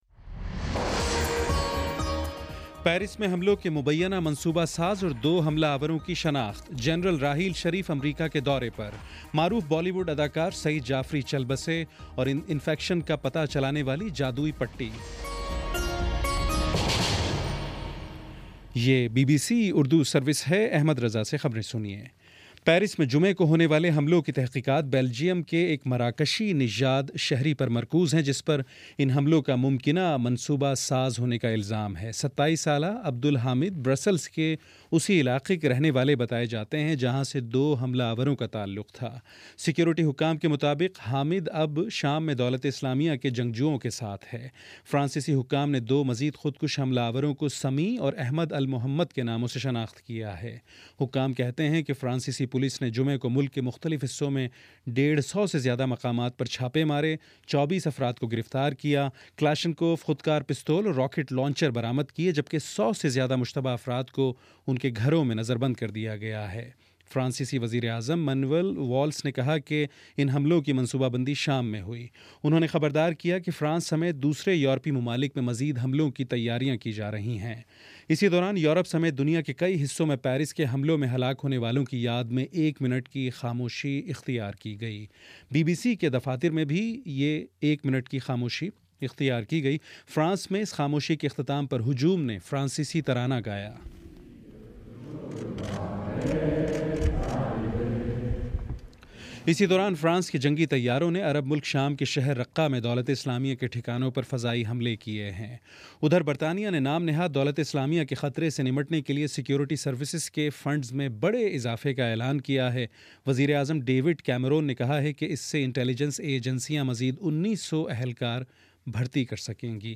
نومبر 16 : شام چھ بجے کا نیوز بُلیٹن